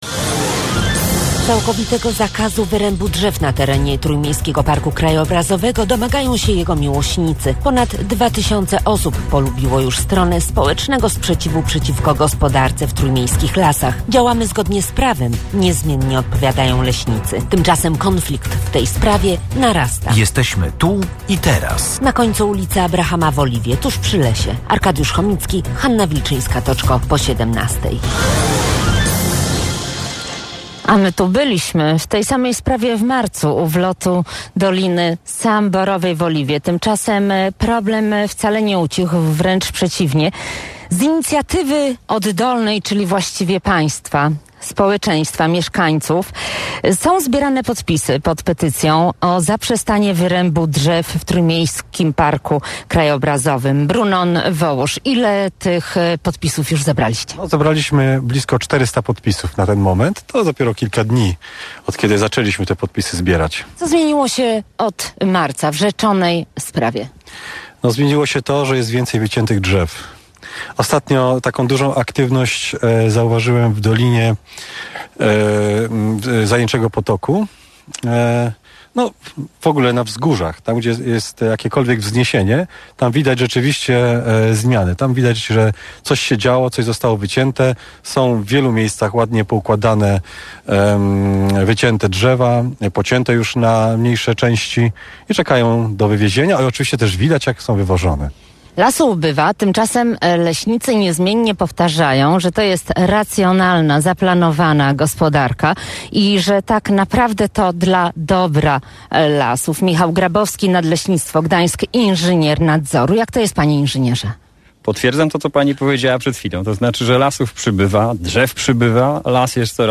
Nasz wóz satelitarny zaparkował przy końcu ulicy Abrahama w Gdańsku – Oliwie. W audycji na żywo wzięli udział obrońcy Trójmiejskiego Parku Krajobrazowego i przedstawiciel Nadleśnictwa Gdańsk.